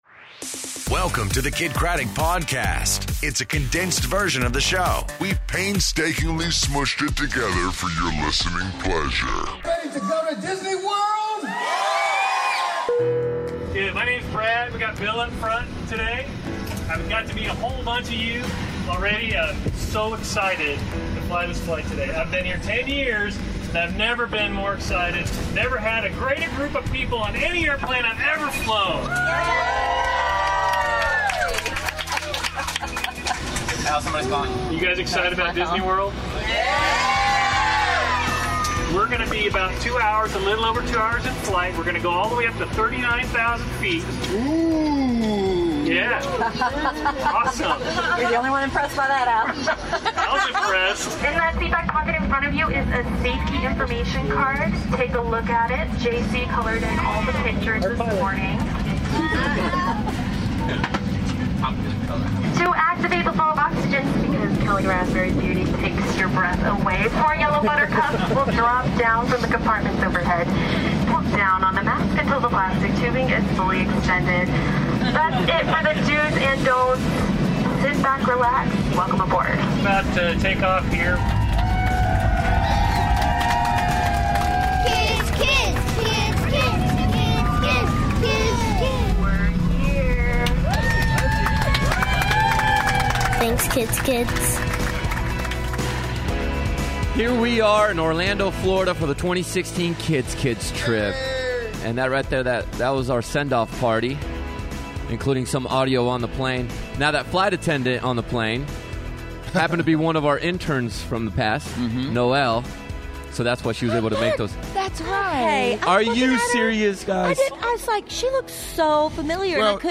Live From Disney World! It's Day 1 Of Kidd's Kids